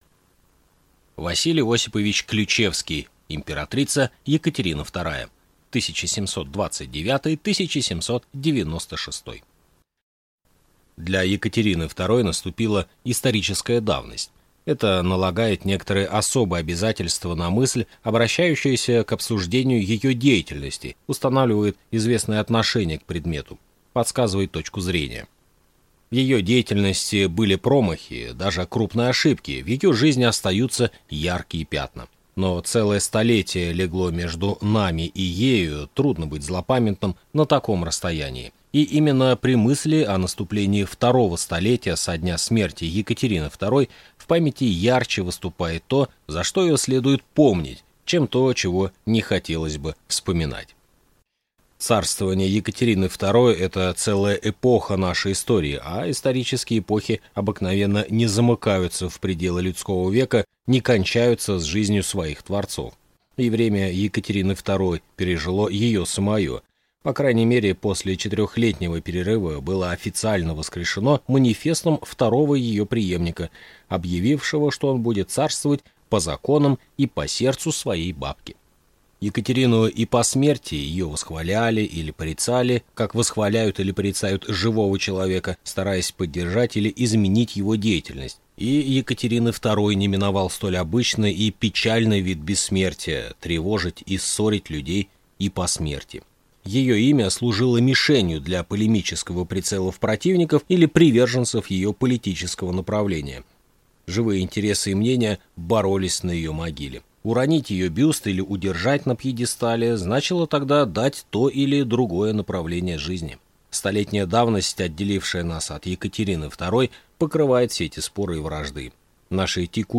Аудиокнига Императрица Екатерина II (1729-1796) | Библиотека аудиокниг